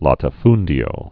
(lätə-fndē-ō)